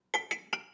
SFX_Cup_02.wav